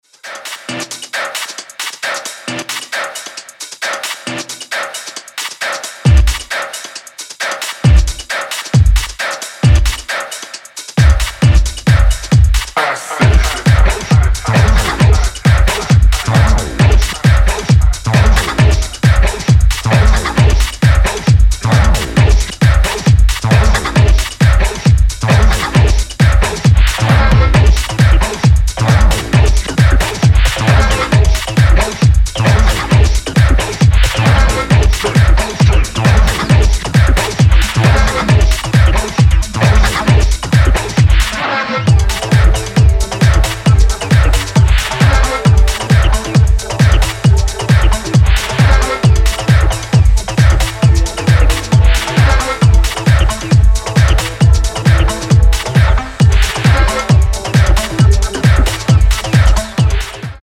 ダークで狂騒的なハウス/テクノを展開しています。